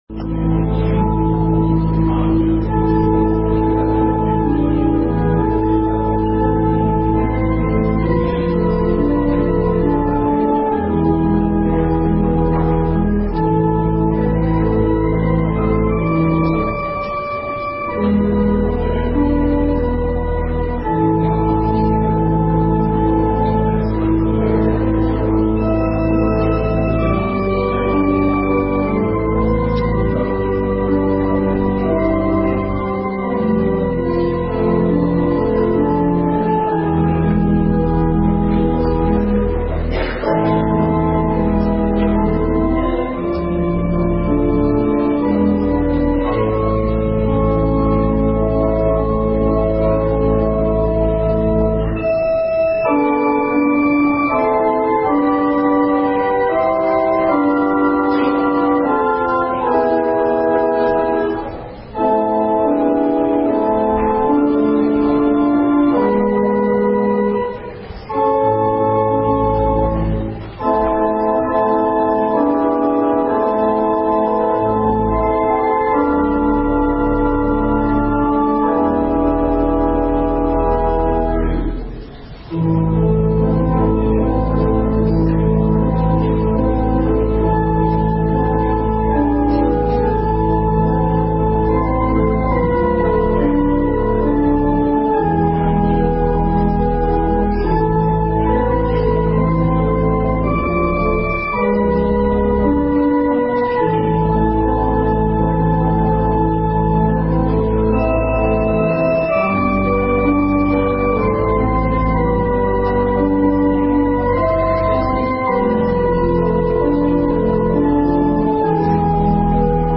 December 16, 2018 Christmas Cantata